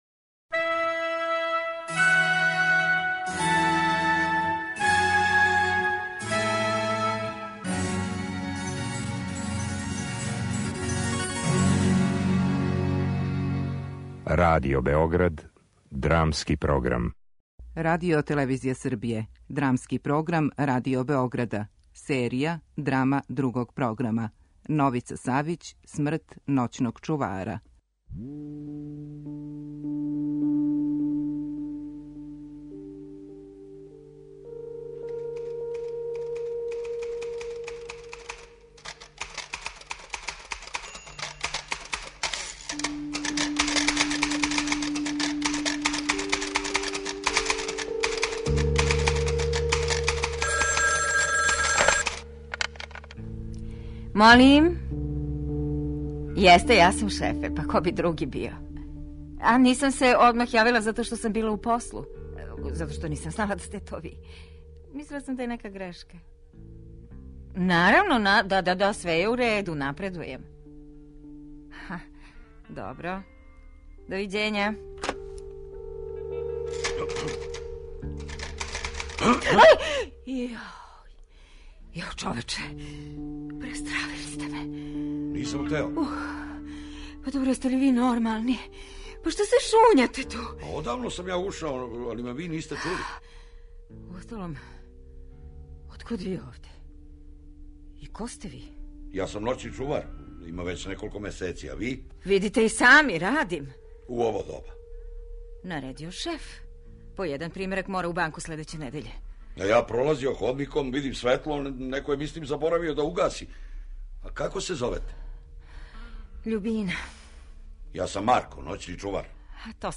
Драмски програм: Драма
Драма